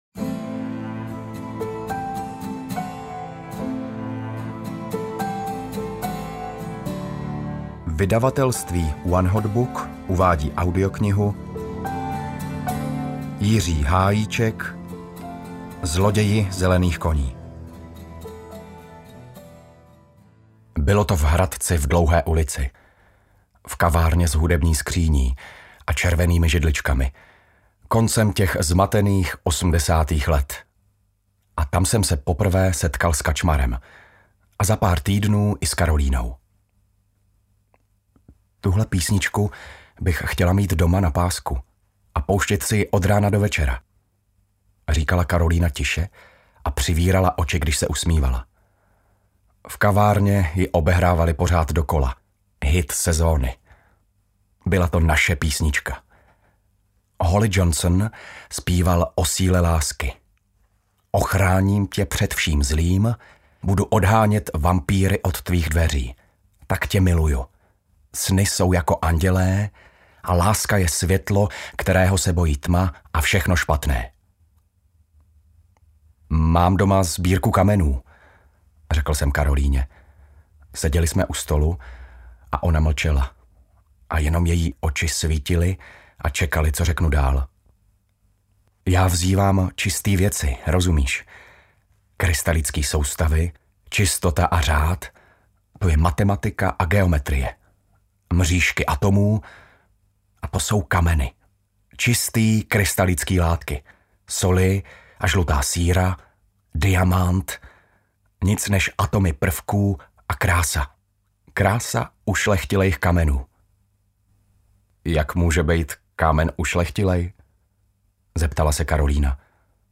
Zloději zelených koní audiokniha
Ukázka z knihy